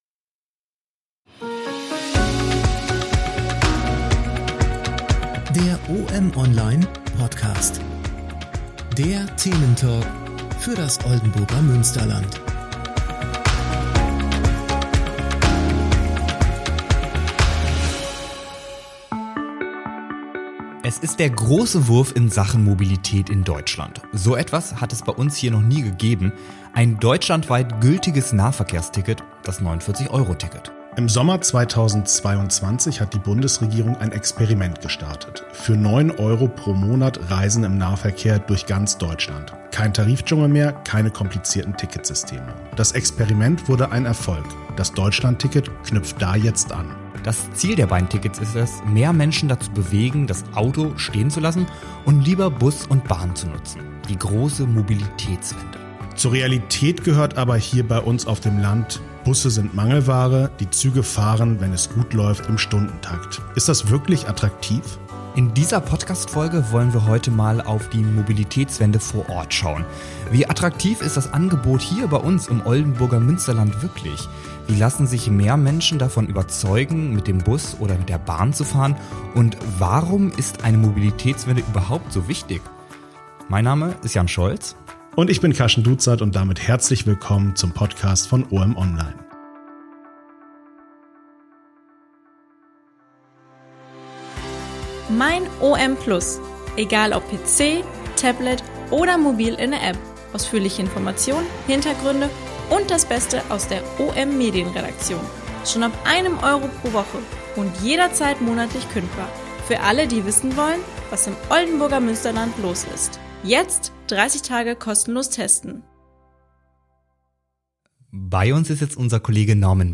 Diese und weitere Fragen diskutieren die Moderatoren